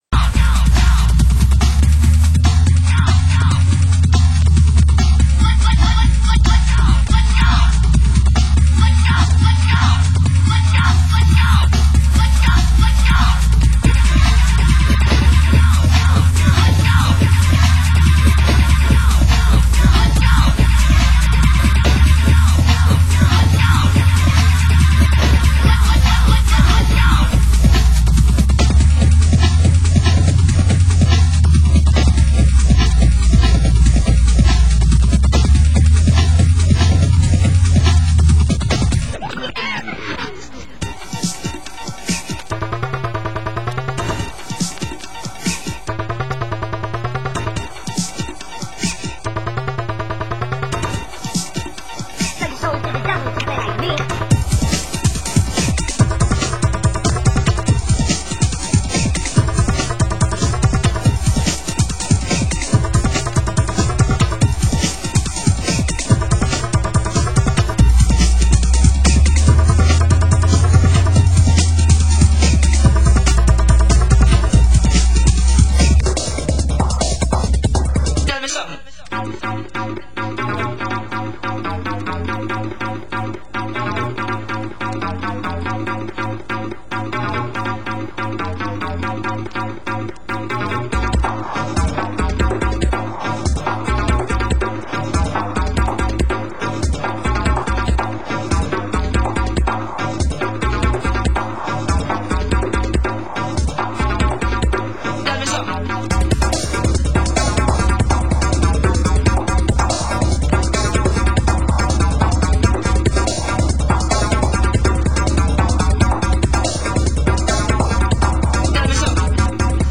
Genre: Drum & Bass